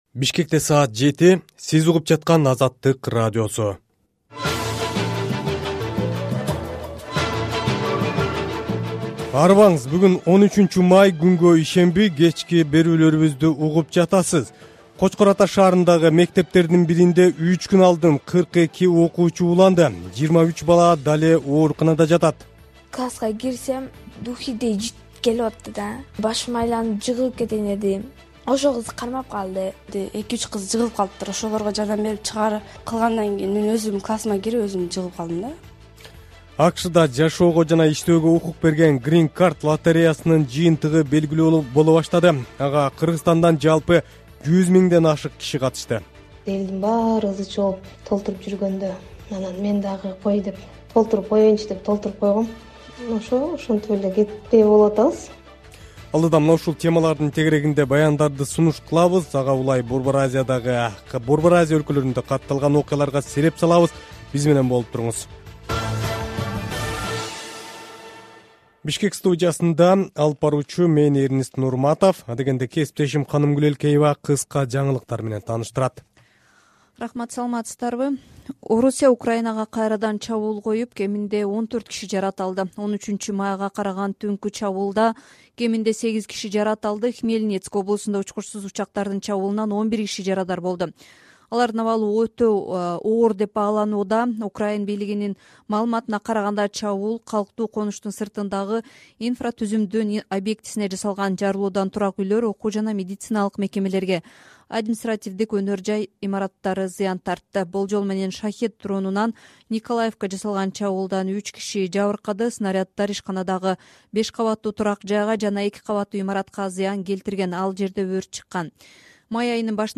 Кечки радио эфир | 13.05.2023 | Ошто кармалгандардан көп сандагы курал-жарак табылды